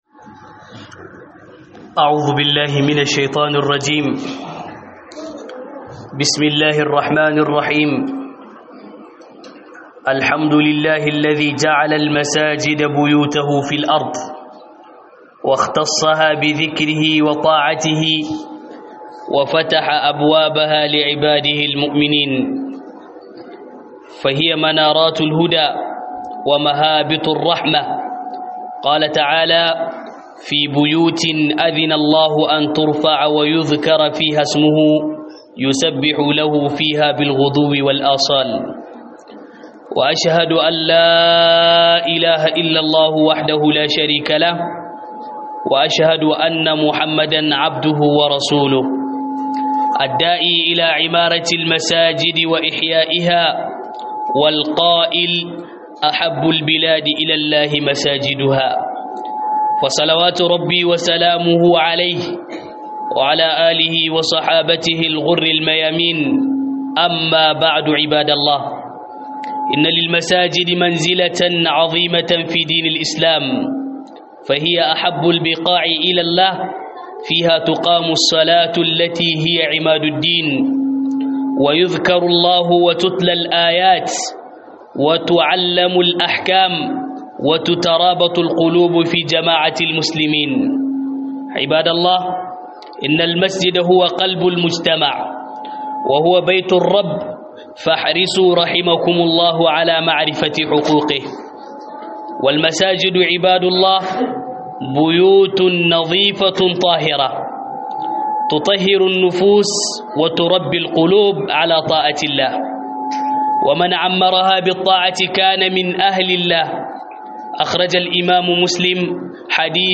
Huɗubar juma'a Raya masallaci